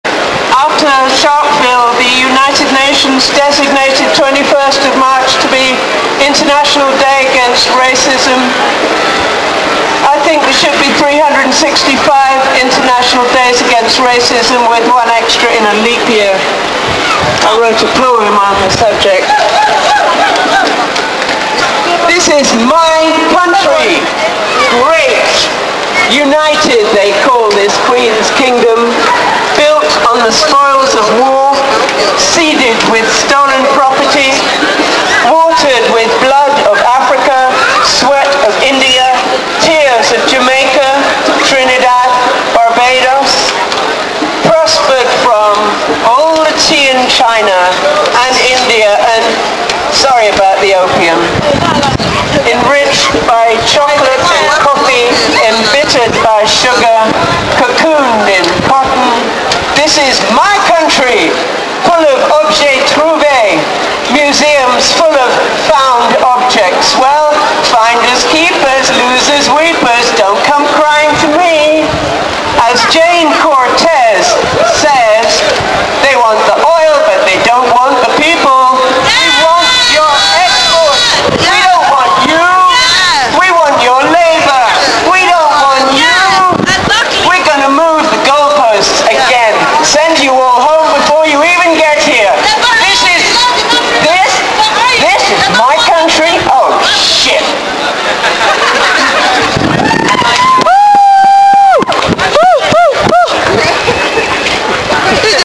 Speak-out against racism at Grey's Monument, open to all anti-racists.
Speaker 3 - mp3 8.2M